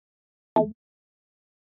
Звуки MacBook Pro и iMac скачать mp3 - Zvukitop